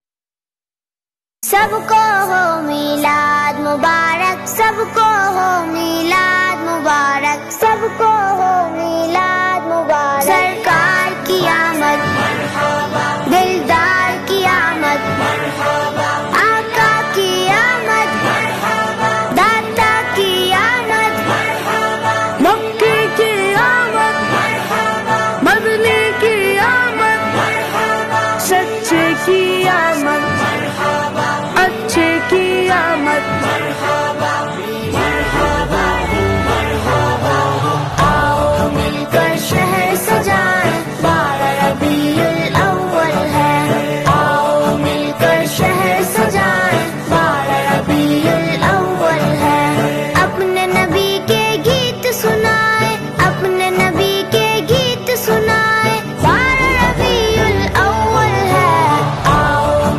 its also a kids nasheed and kids song .